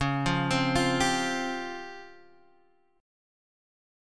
DbM7b5 chord